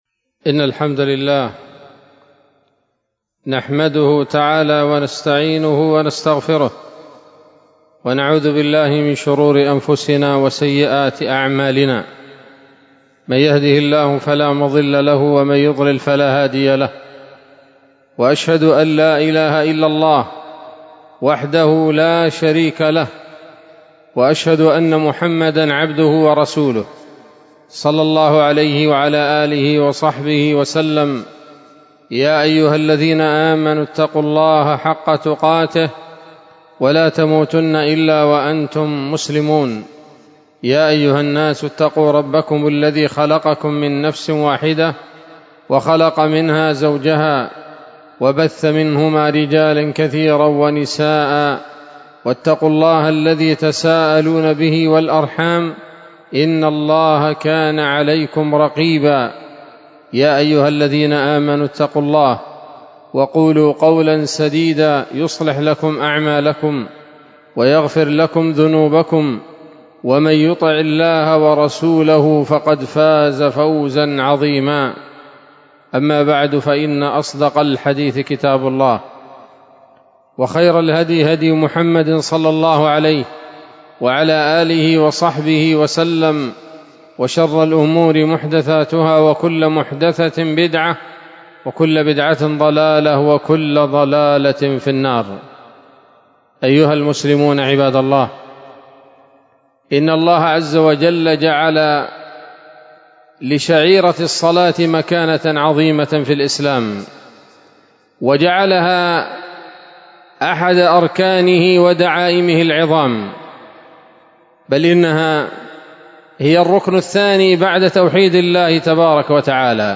خطبة جمعة بعنوان: (( صلاة الفجر أيها الغافلون )) 26 جمادى الآخرة 1446 هـ، دار الحديث السلفية بصلاح الدين